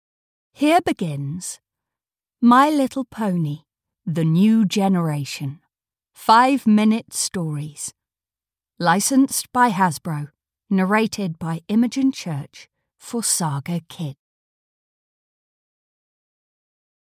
Audio knihaMy Little Pony - The New Generation - 5-Minute Stories (EN)
Ukázka z knihy
my-little-pony-the-new-generation-5-minute-stories-en-audiokniha